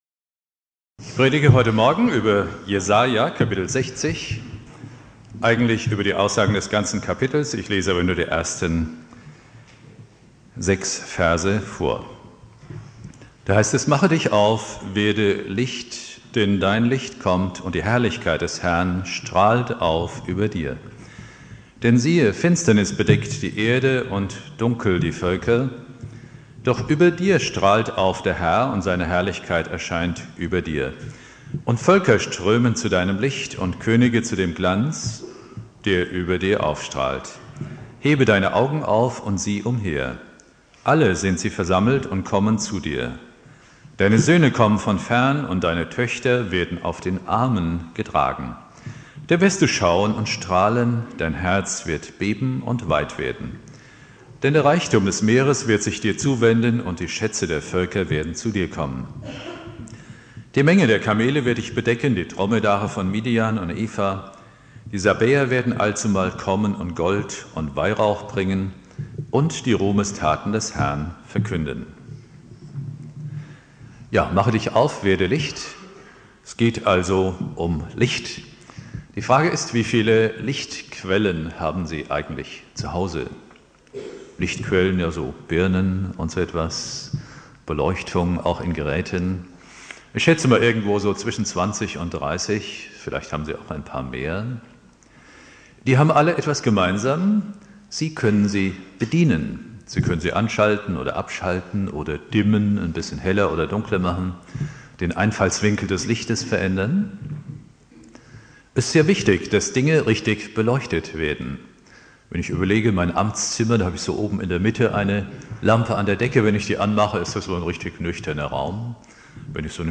Predigt
2.Weihnachtstag